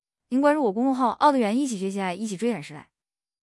3倍语速mp3格式:
OddTTS-kokoro-3倍速mp3格式.mp3